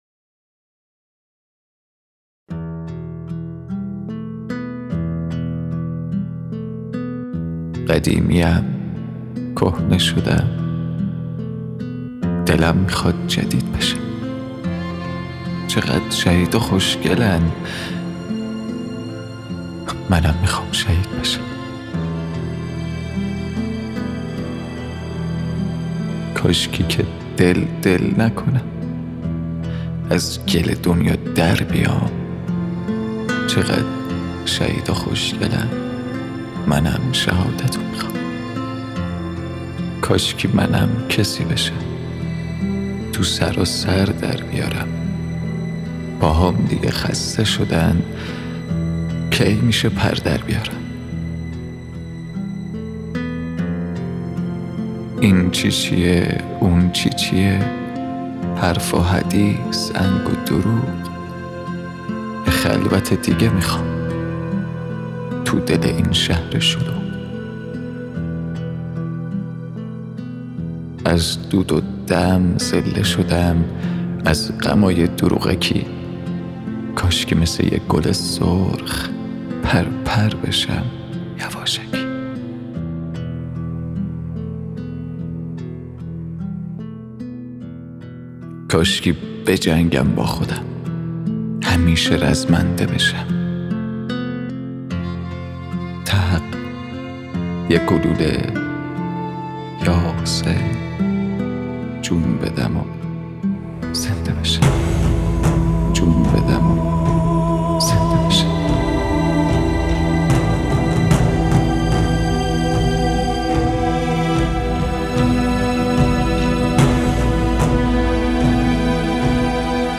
دکلمه
ویولون